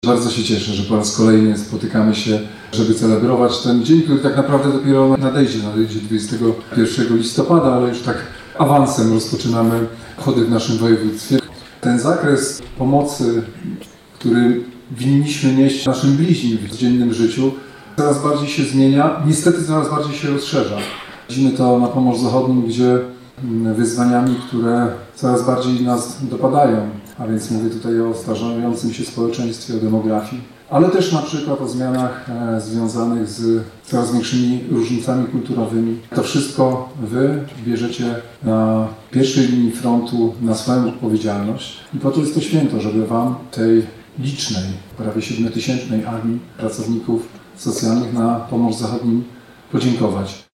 Tegoroczna uroczystość odbyła się w Teatrze Polskim w Szczecinie.
– mówił podczas uroczystości Marszałek Województwa Zachodniopomorskiego, Olgierd Geblewicz.